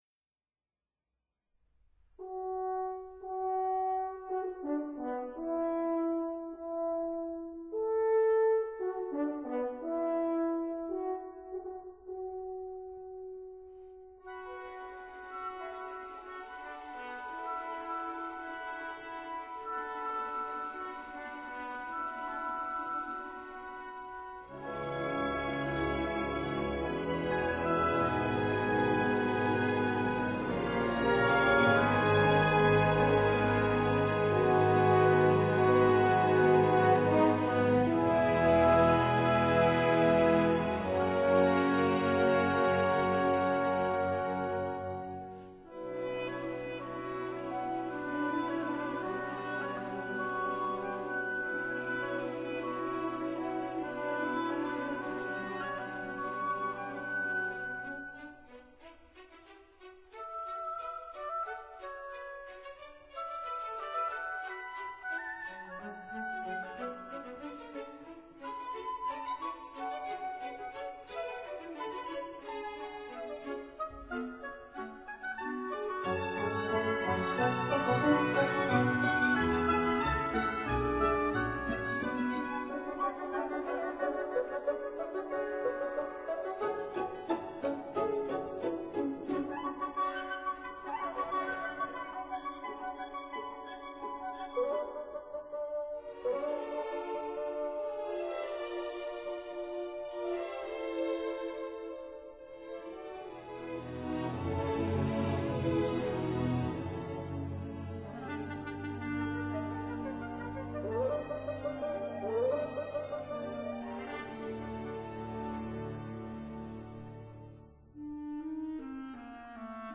conductor